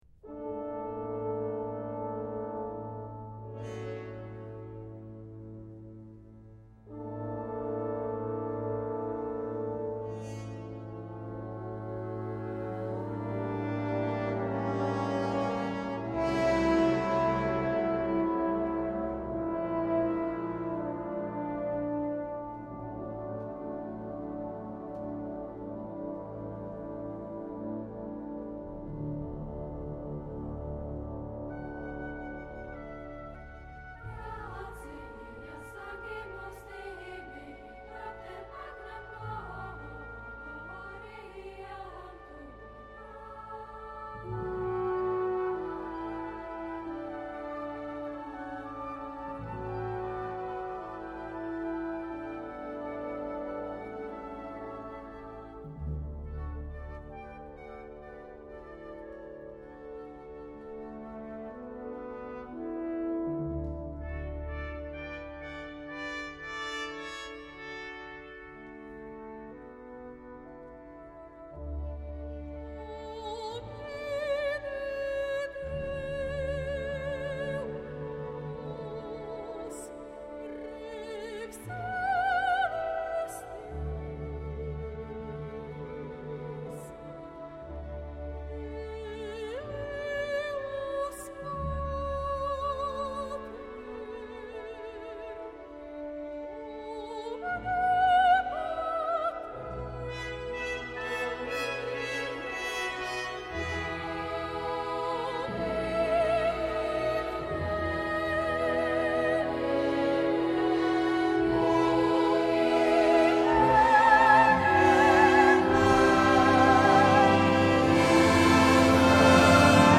orch. verze 1